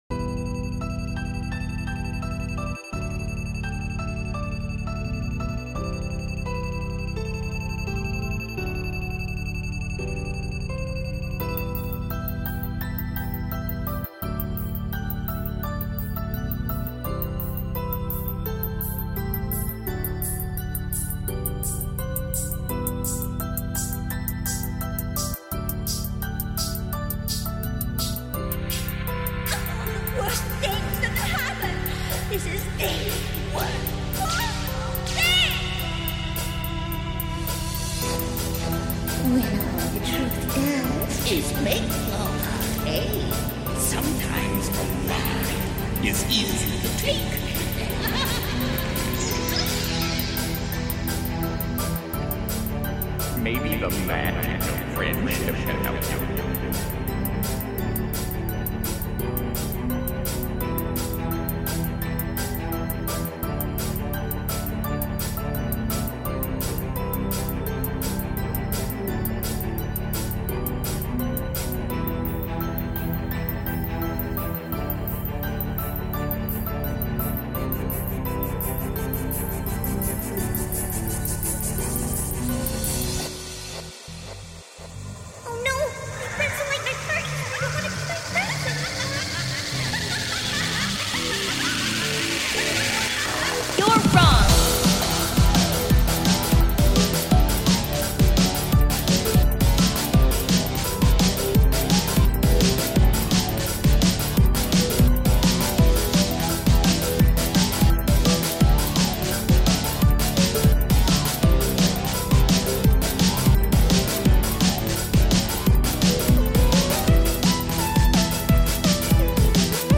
DnB